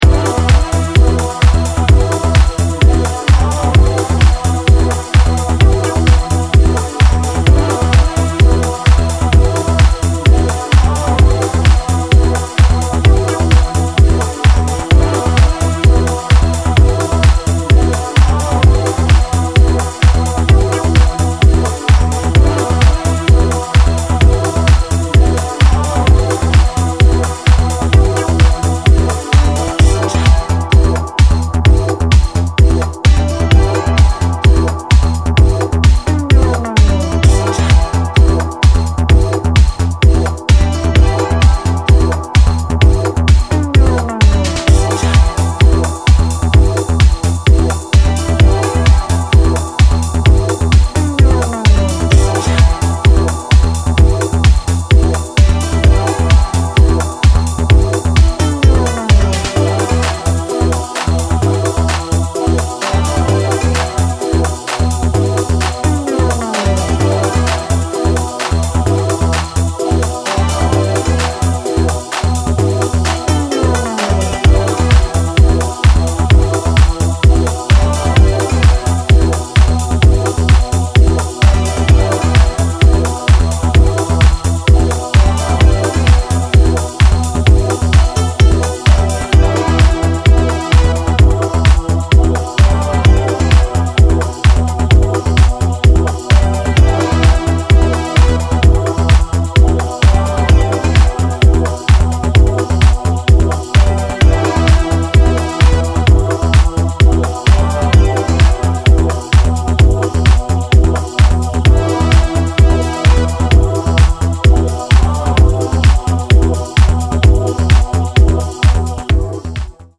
オールドなシカゴ・アシッドをモダンかつ煌びやかなタッチで昇華したような